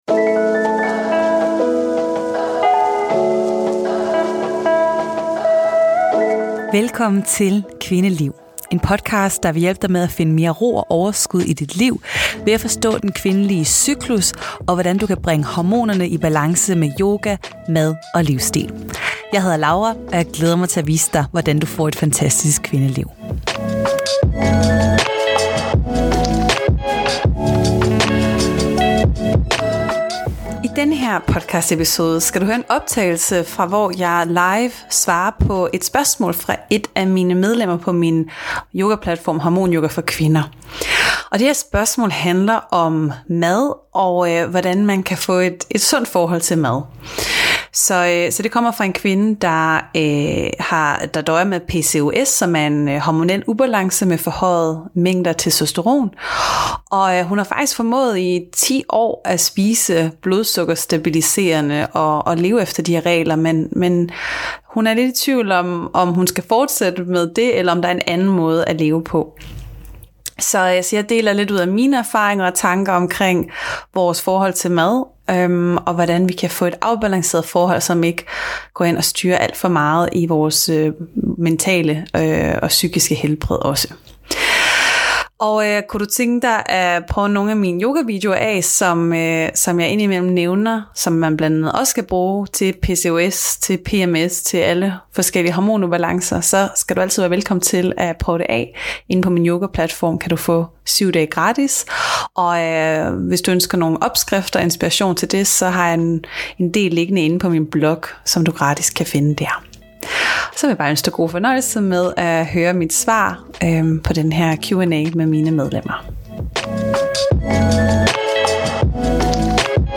I denne episode gør vi det til gengæld mere konkret og dykker ned i et spørgsmål om PCOS og blodsukkeret. Episoden er fra en af mine live spørgetimer med mine medlemmer, og den er relevant for dig, hvis du er nysgerrig på, hvordan du får et sundt og afbalanceret forhold til din mad.